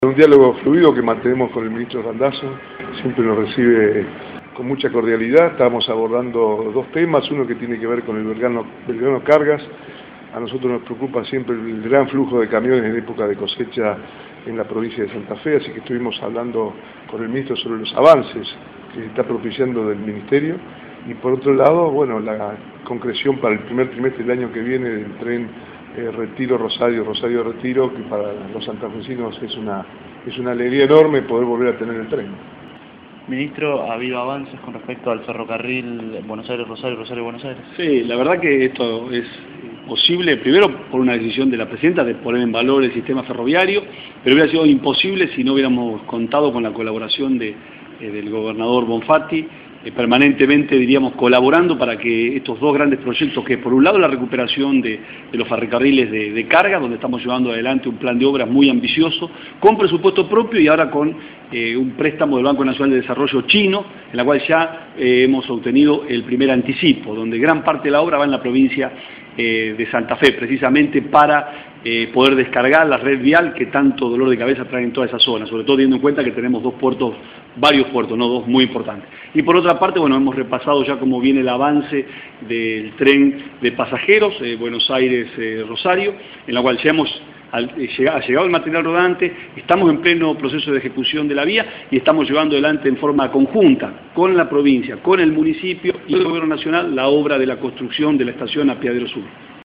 Declaraciones de Bonfatti y Randazzo sobre la reunión.